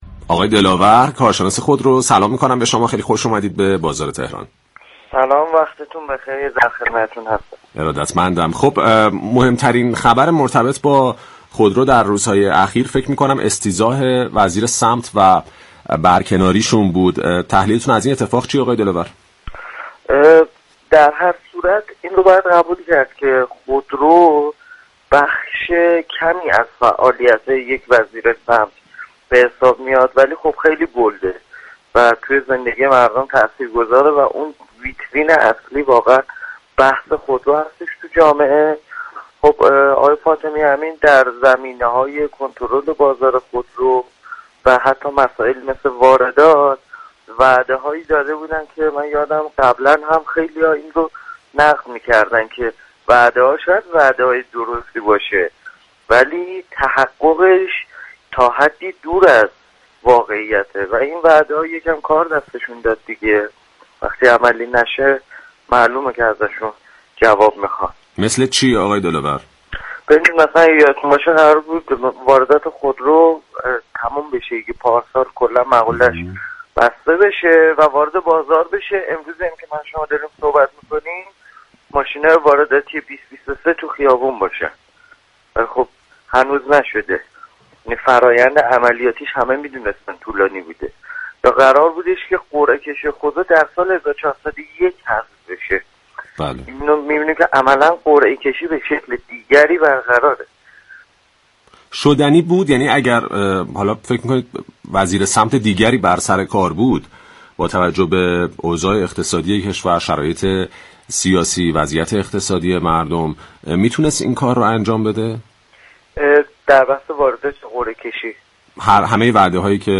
كارشناس بازار خودرو در گفت و گو با «بازار تهران»